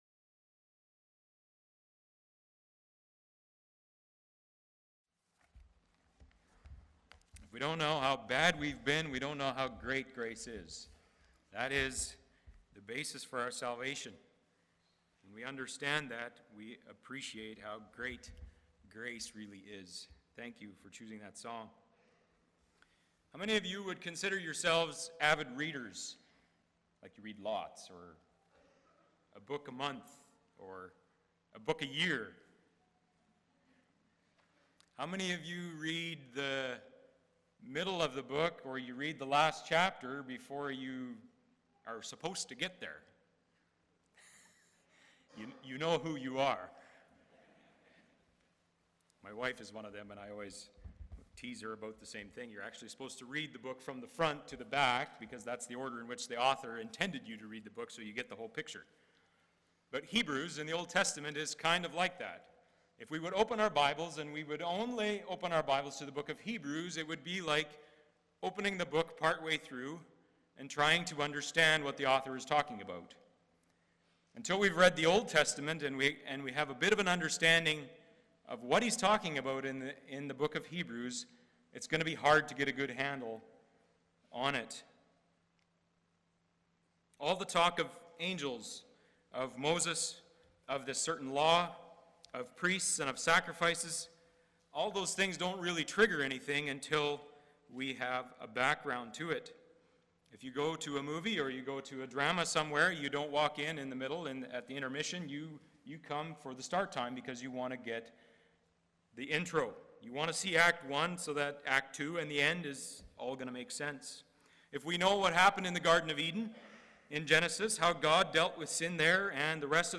Hebrews 8:1-13 Service Type: Sunday Morning « Church Bible Study